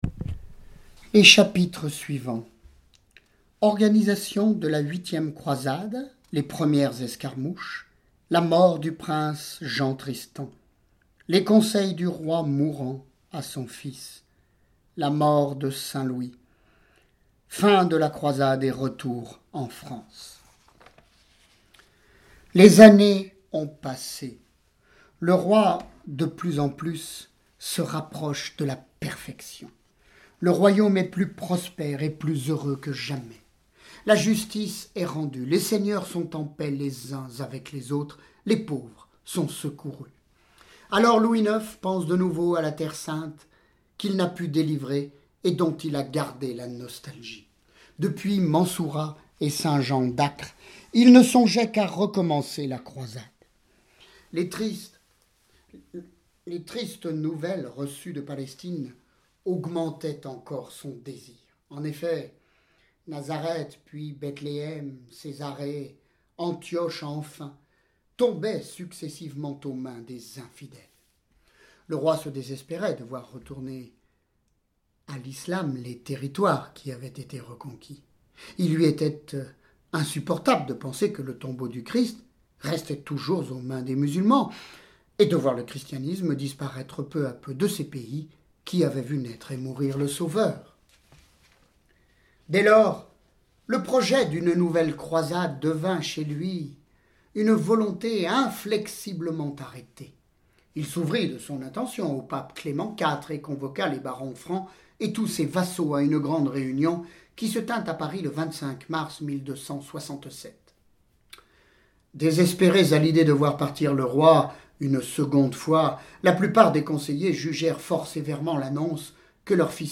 Lecture de vies de Saints et Saintes